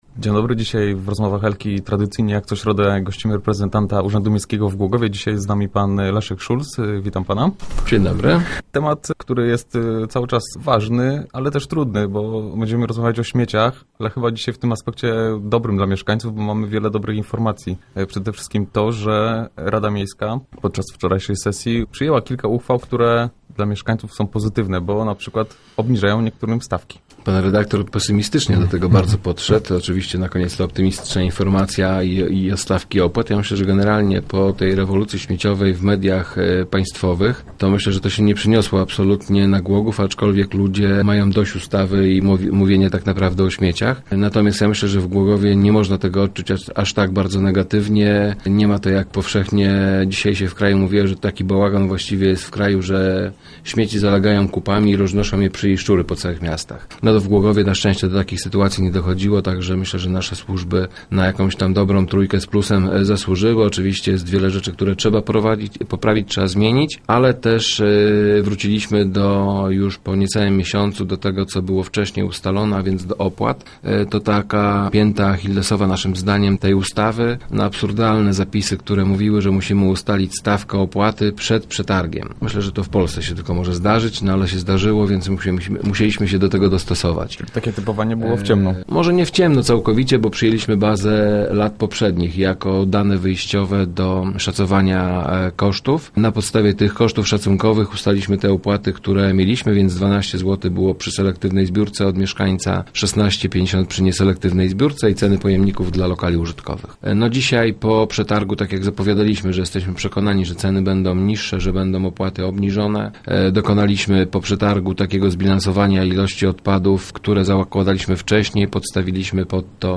O szczegółach opowiada wiceprezydent Leszek Szulc, który był gościem Rozmów El...